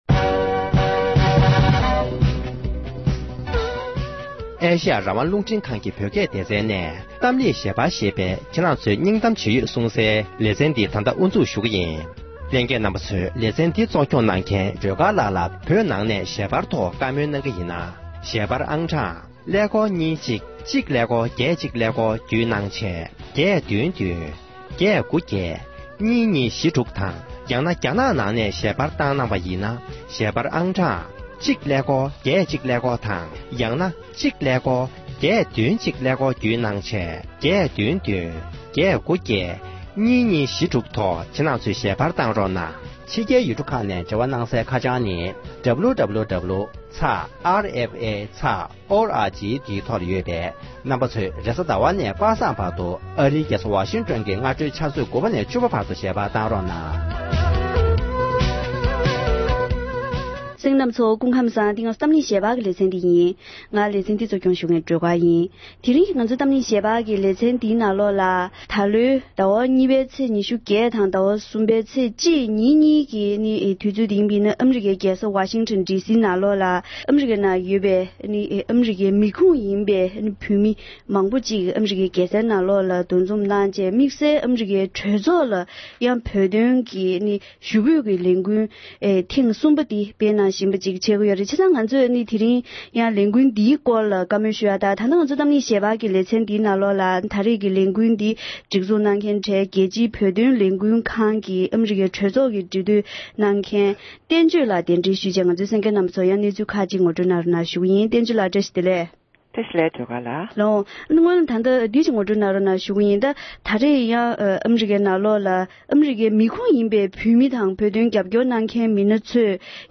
བོད་དོན་ཆེད་དུ་ཨ་རིའི་གྲོས་ཚོགས་ནང་ཞུ་འབོད་ཀྱི་ལས་འགུལ་སྤེལ་མཁན་མི་སྣ་ཁག་དང་ལྷན་དུ་བཀའ་མོལ་ཞུས་པ།